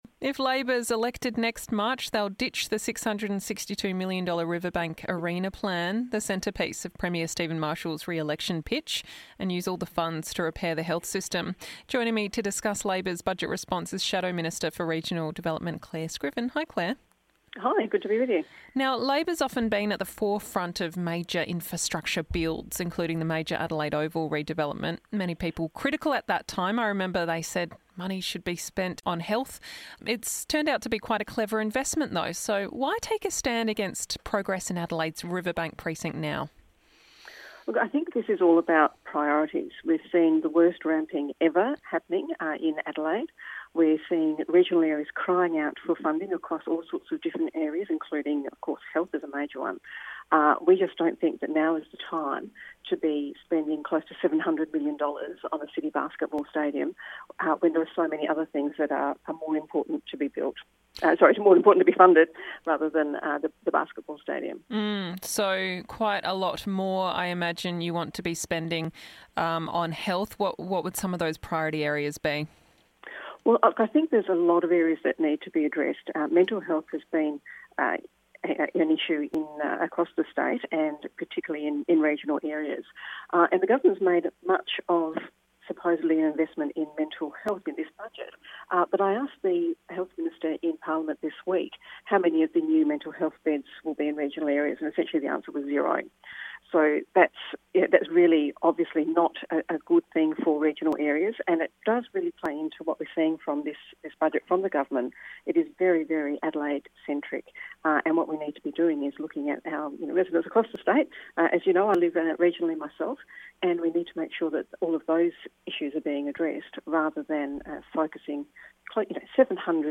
SA's Shadow Regional Minister Responds to the Budget